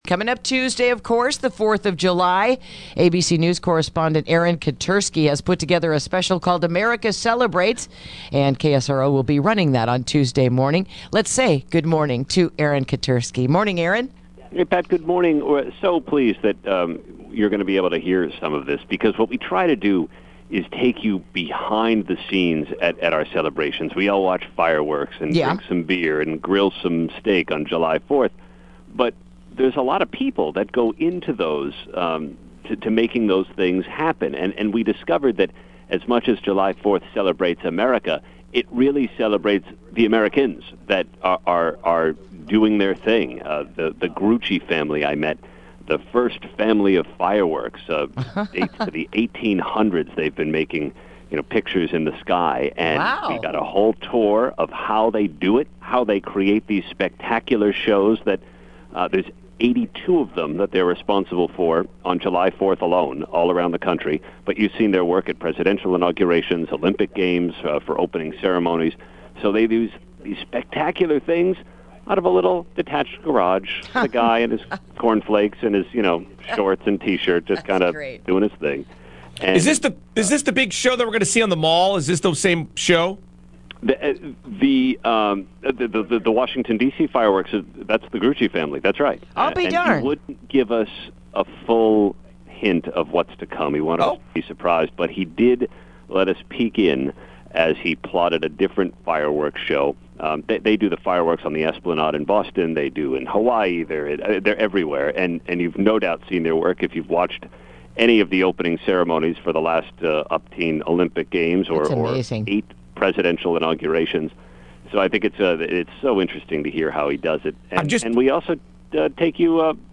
Interview: America Celebrates It’s Birth